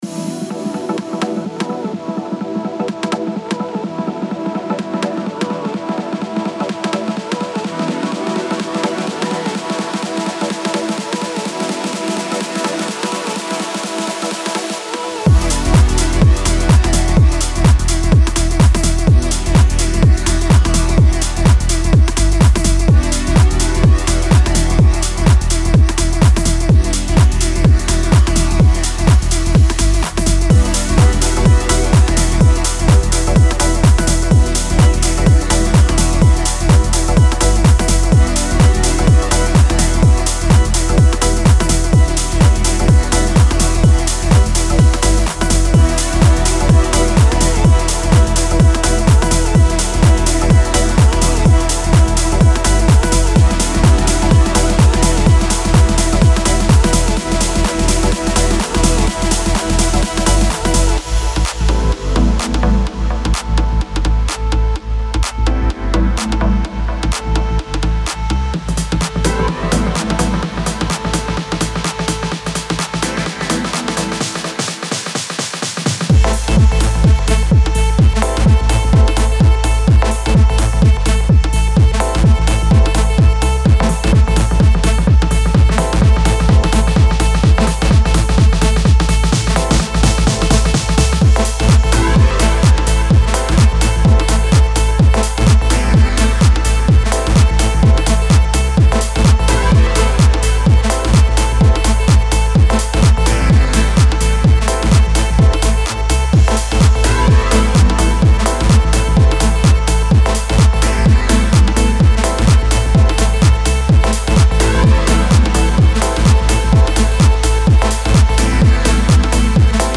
All Kits BPM & Key-Labelled. (Kits BPM 126 – 128).